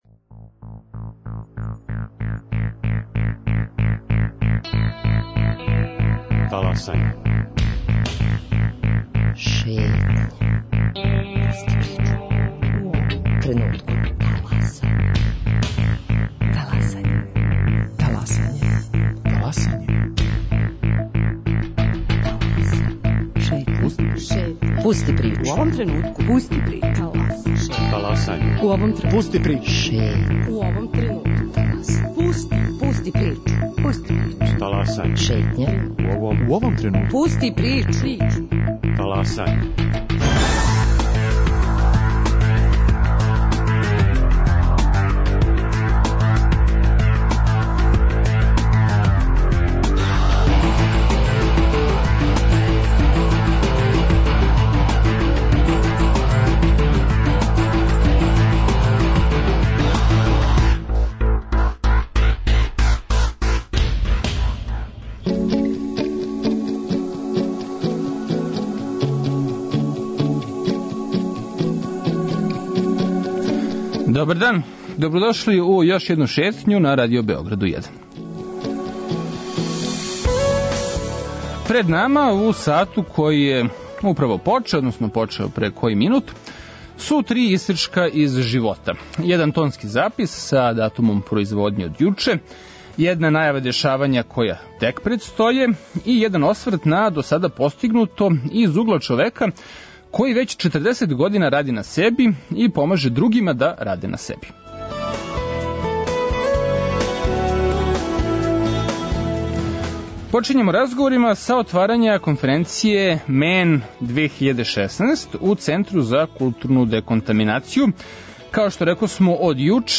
На старту Шетње - запис за трибине о родној равноправности одржане јуче у Центру за културну деконтаминацију. У оквиру првог дана скупа MAN 2016, који организује Центар Е8, тражио се одговор на питање 'Ко је прави мушкарац', а своје мишљење понудили су неки од досадашњих добитника награде Прави мушкарац.